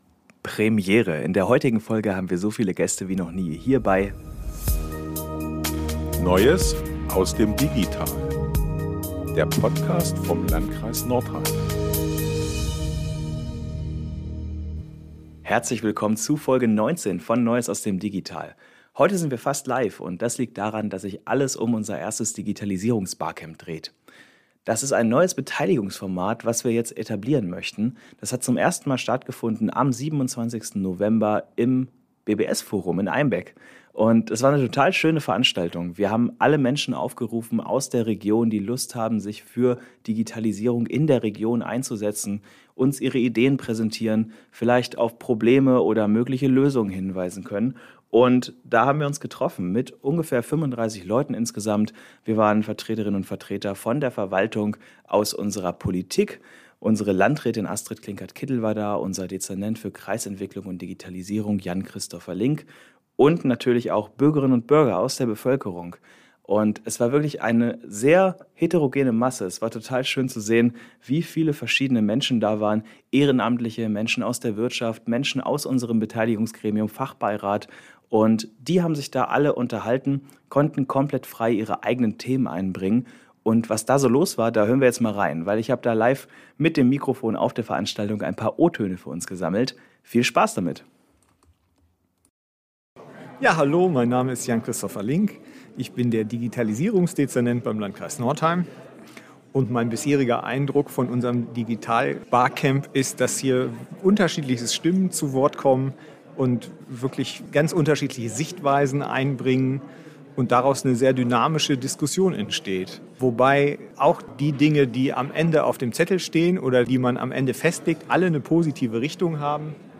In dieser Folge präsentieren wir euch einige Eindrücke und O-Töne live von der Veranstaltung und geben einen Ausblick, wie es mit dem neuen Beteiligungsformat weitergeht.